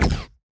weapon_laser_010.wav